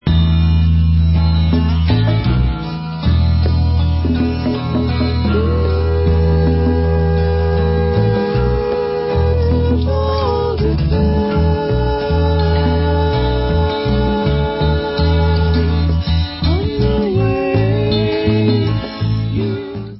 LOST CLASSIC OF 60'S UK ACID FOLK/BAROQUE PSYCHEDELICA